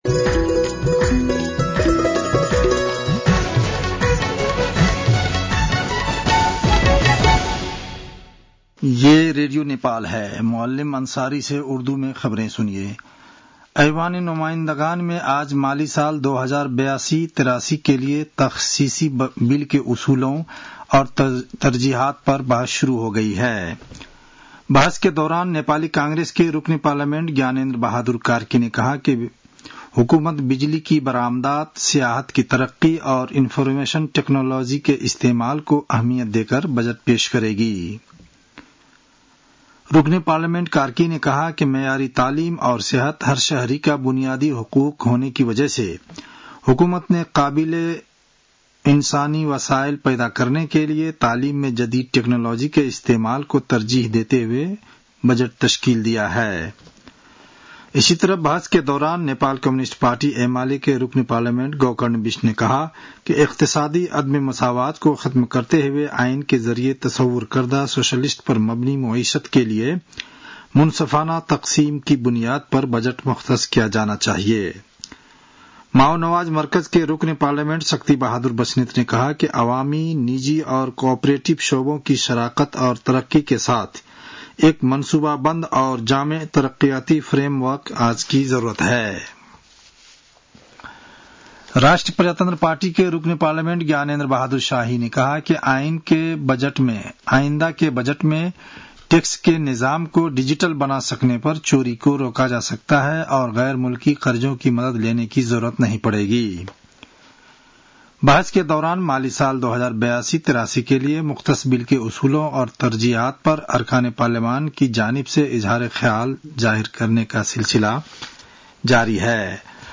उर्दु भाषामा समाचार : ३० वैशाख , २०८२